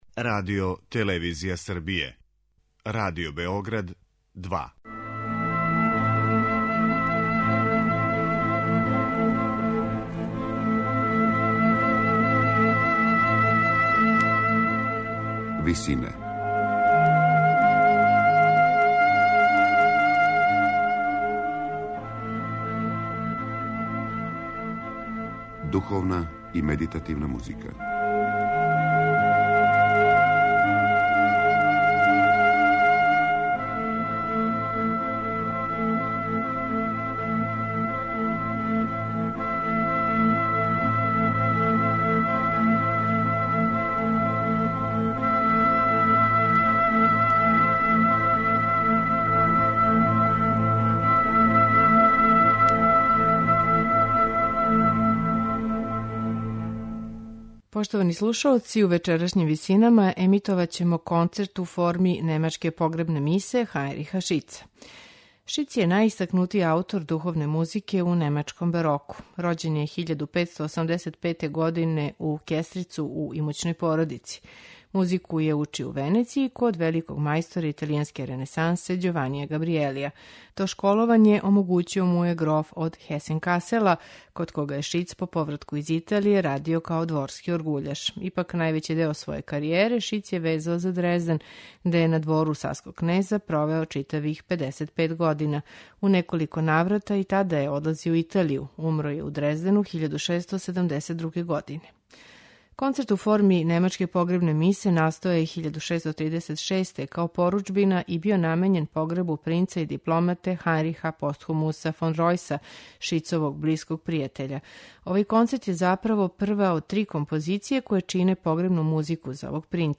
Духовни концерт Хајнриха Шица
Шиц је за овај духовни концерт предвидео иновативну вокалну структуру у којој се шестогласни одсеци смењују са дуетима и терцетима.